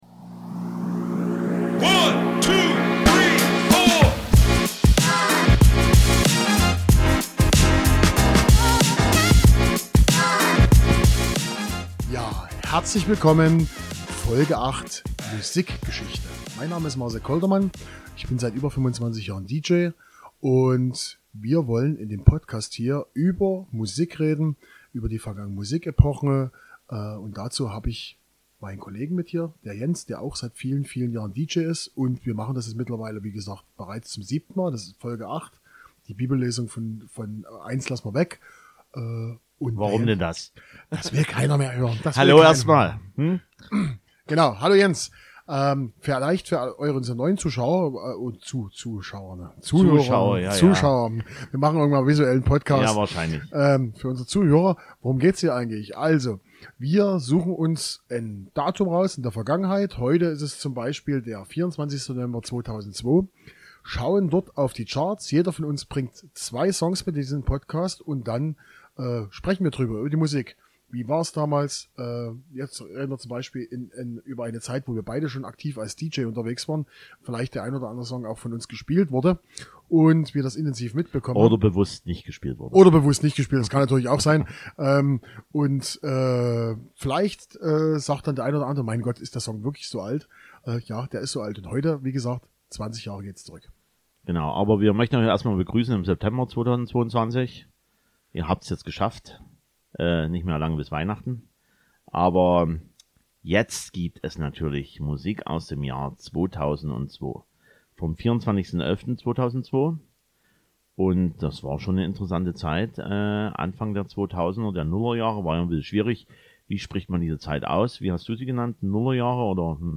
Wir haben wieder 4 Songs im Podcast, die nun auch schon 20 Jahre alt sind.